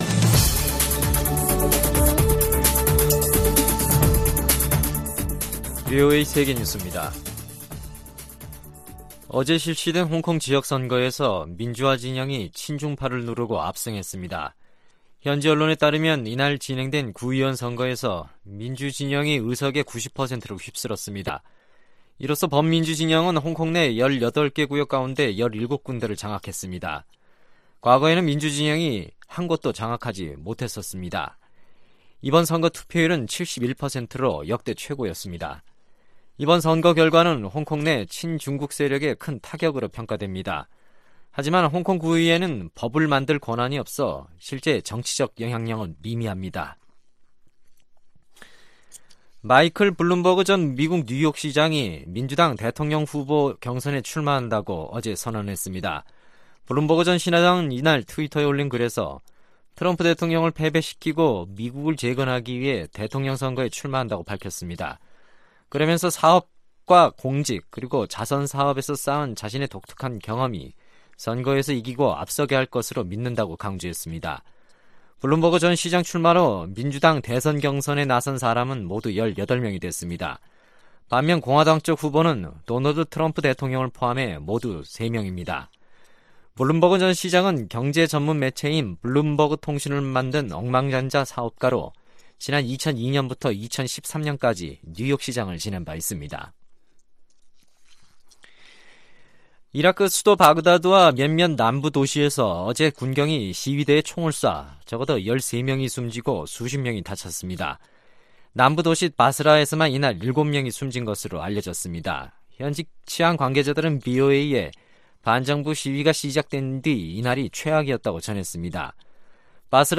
VOA 한국어 간판 뉴스 프로그램 '뉴스 투데이', 2019년 11월 25일 2부 방송입니다. 북한은 남북 접경 지역서 해안포 사격을 실시했습니다.